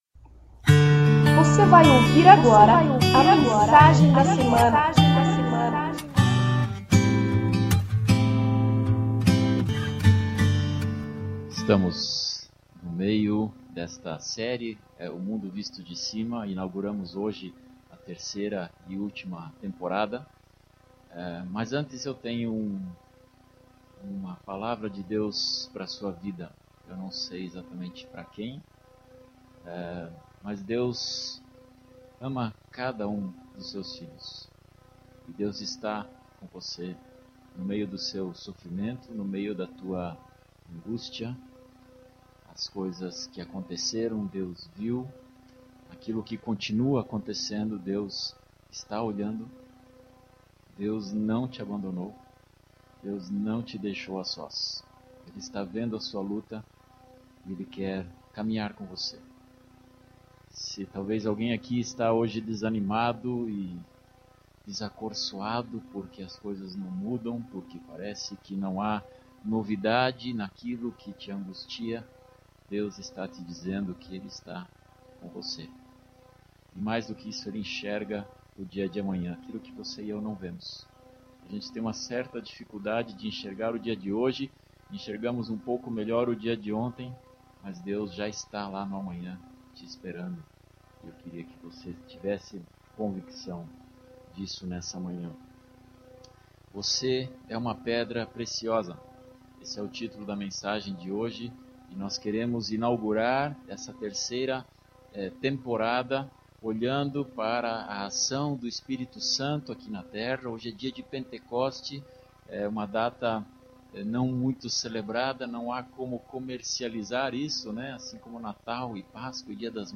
Igreja Evangélica Menonita - Água Verde - VOCÊ É UMA PEDRA PRECIOSA